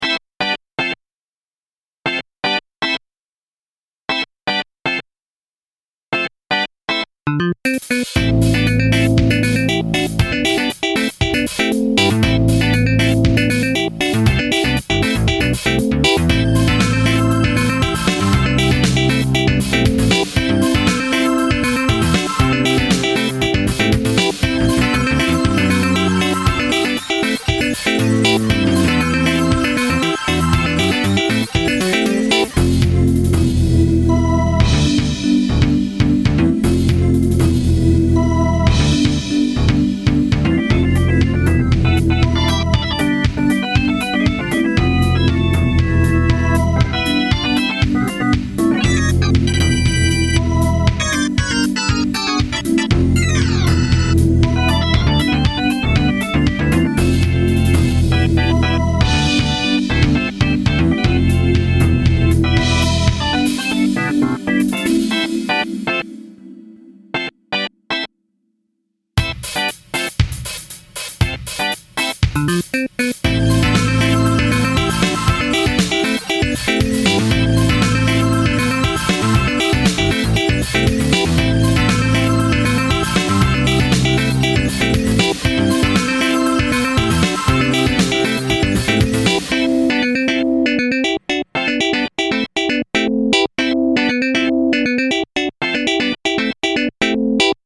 タグ: 楽しい,あやしい
楽しさ+あやしさ
軽快に動く動く